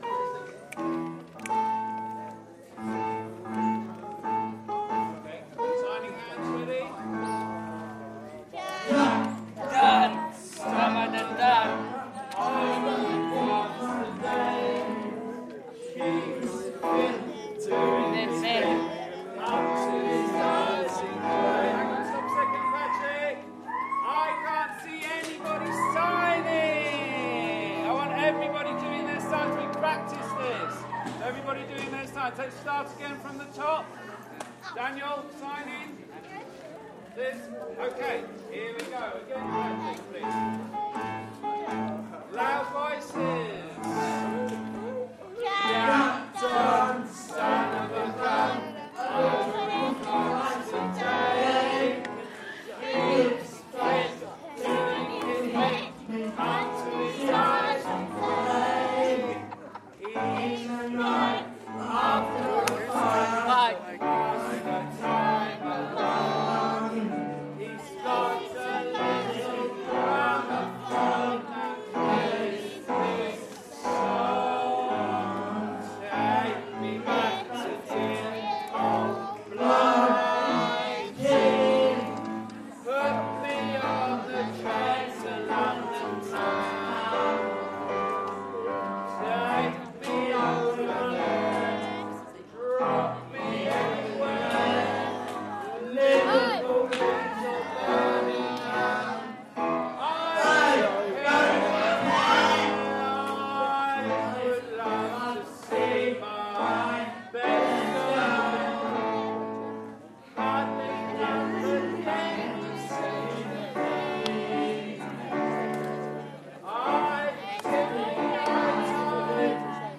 Both choirs singing at the Armistice Assembly. (November 2014)